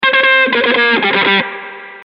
标签： 120 bpm Rock Loops Guitar Electric Loops 344.62 KB wav Key : Unknown
声道立体声